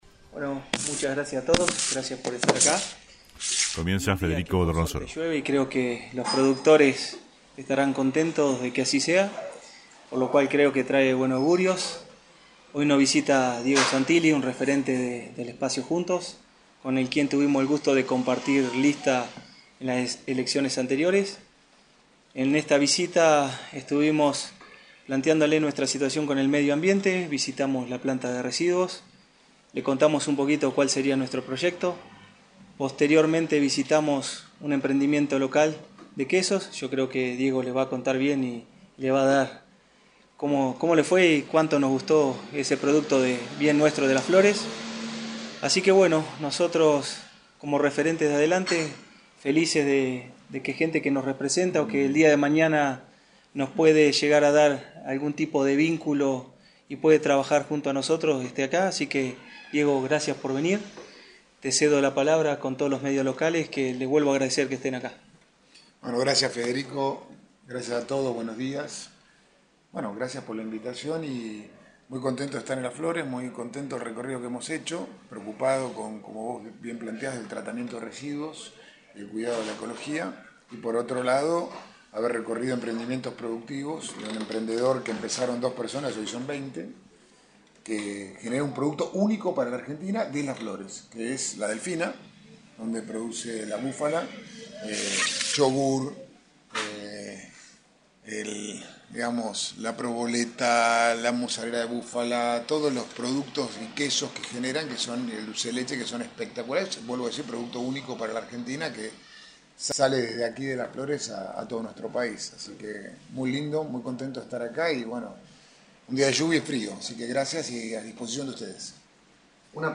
Luego de una recorrida por diversos sectores de la ciudad (Planta de Tratamiento de Residuos, empresa Lácteos «La Delfina», etc.) y acompañado por el concejal y candidato a intendente Federico Dorronsoro (UCR), Santilli brindó una conferencia de prensa en un reconocido bar céntrico.
Conferencia-Santilli.mp3